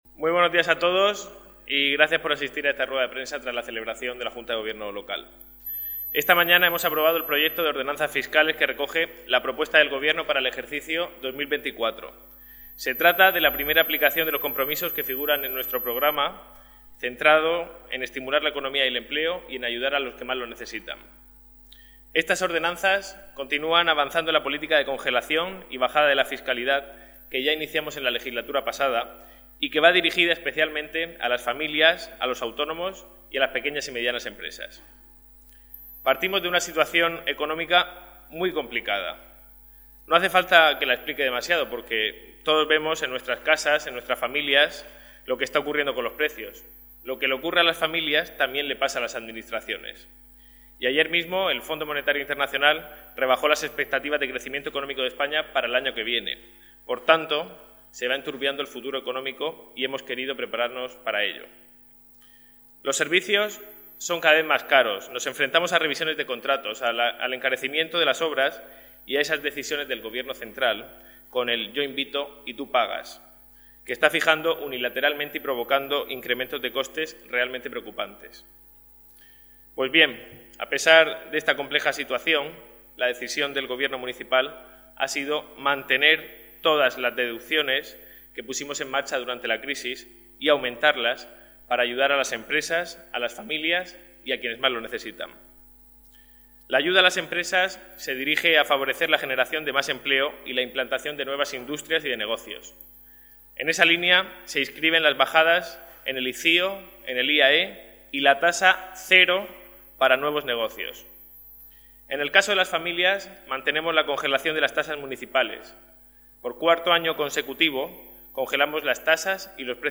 Enlace a Declaraciones del concejal de Hacienda del Ayuntamiento de Cartagena, Nacho Jáudenes